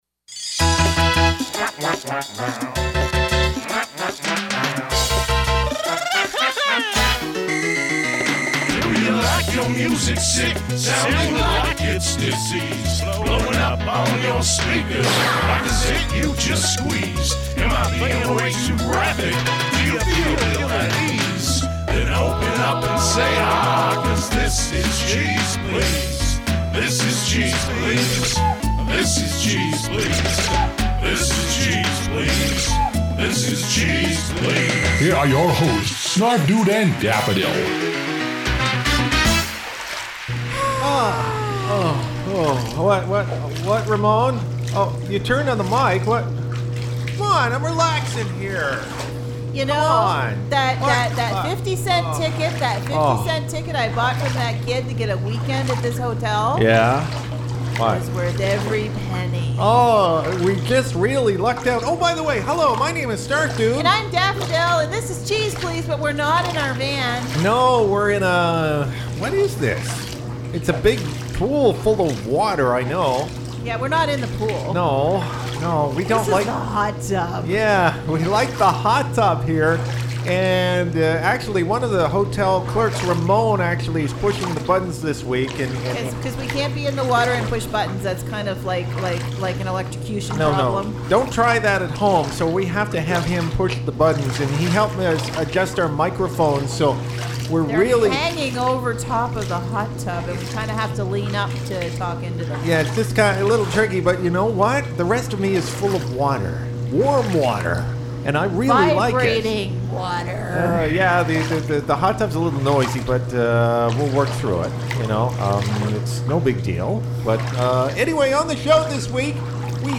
Our Hosts lucked into a free hotel night stay and decided to host the show from the hot tub!
File Information Listen (h:mm:ss) 0:29:21 Cheeze Pleeze # 533 Download (8) cheeze_125.mp3 56,366k 256kbps Stereo Comments: Live...From the Hot Tub!